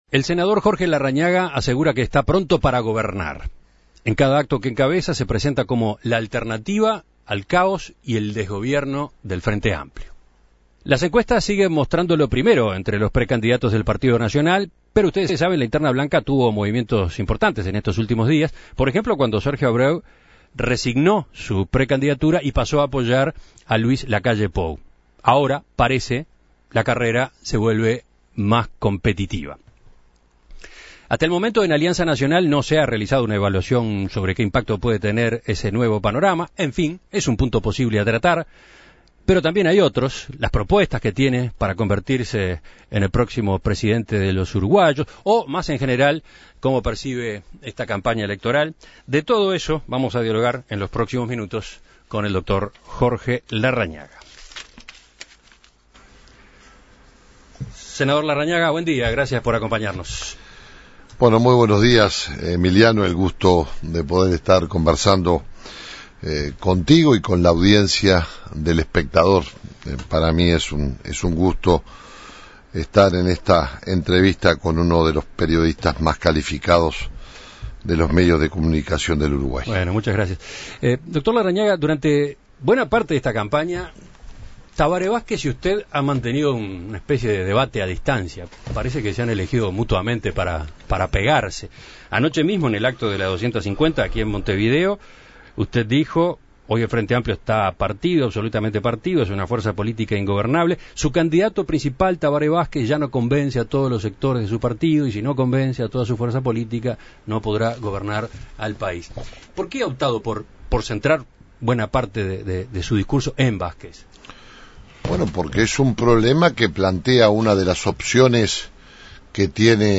El senador Larrañaga conversó con En Perspectiva sobre sus propuestas de cara a junio (y eventualmente a octubre) y cómo percibe la campaña.